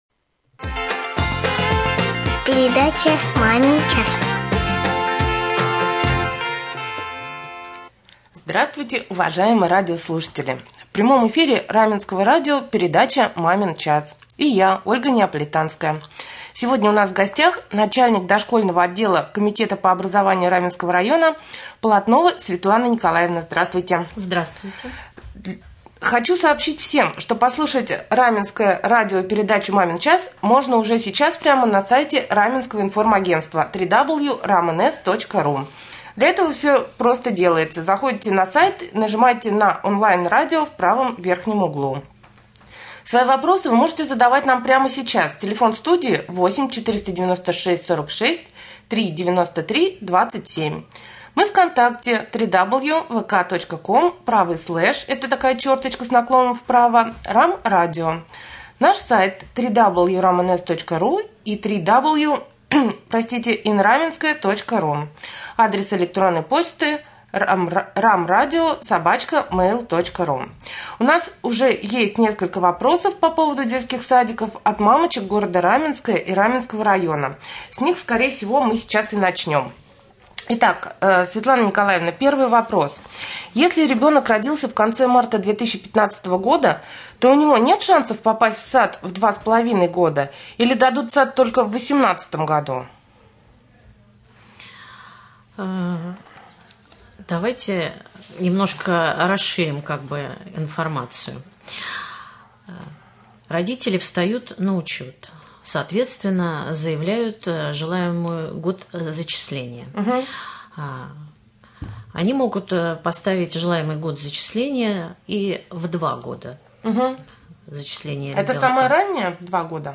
в прямом эфире Раменского радио в передаче «Мамин час».